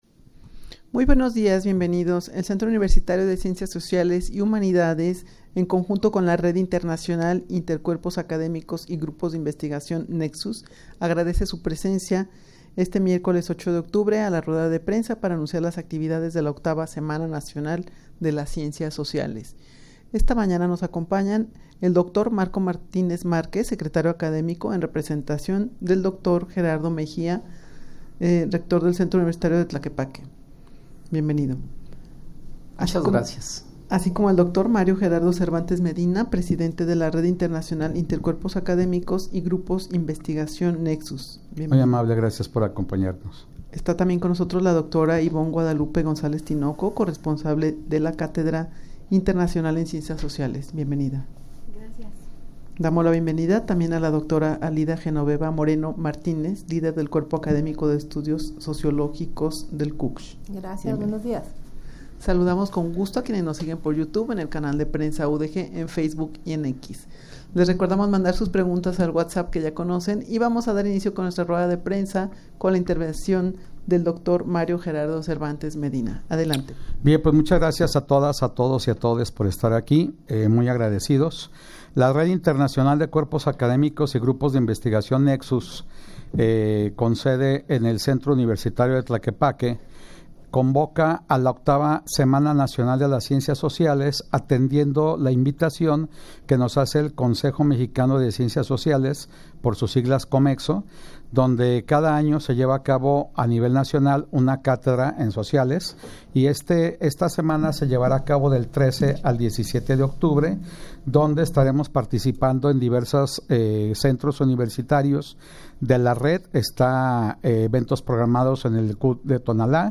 Audio de la Rueda de Prensa
rueda-de-prensa-para-anunciar-las-actividades-de-la-8ava-semana-nacional-de-las-ciencias-sociales.mp3